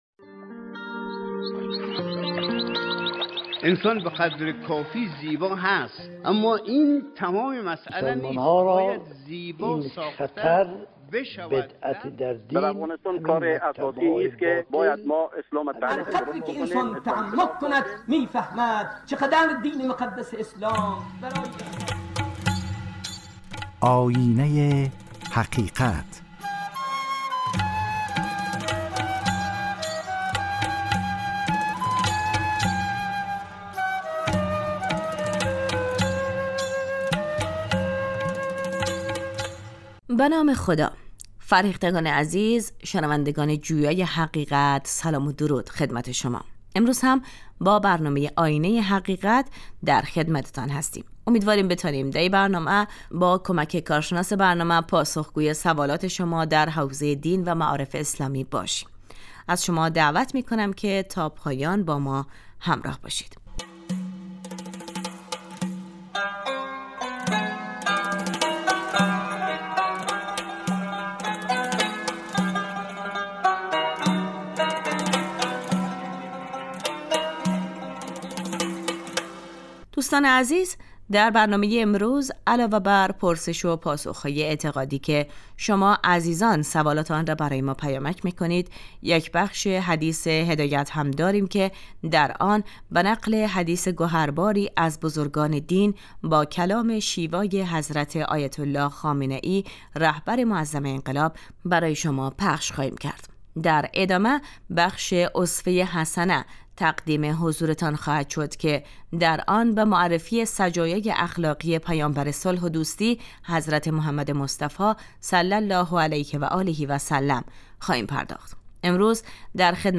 گوینده : سرکار خانم
پرسش و پاسخ های اعتقادی